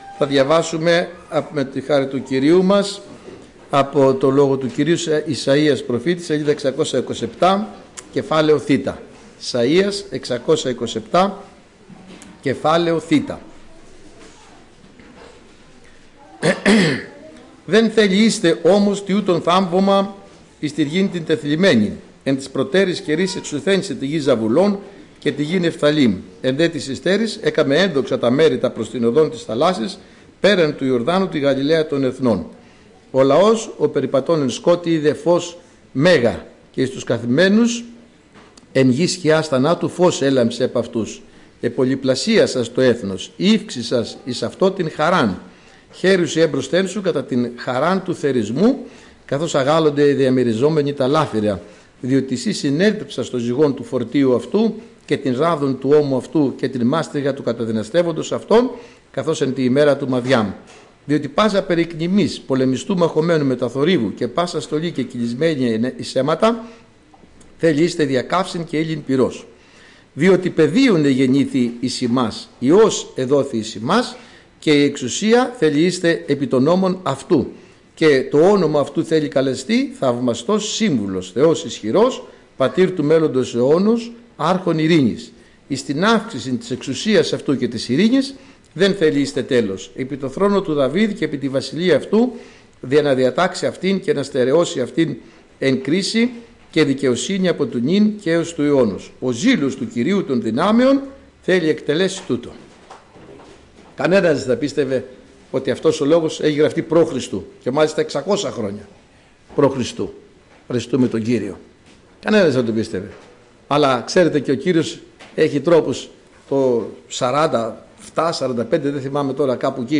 Κήρυγμα Κυριακής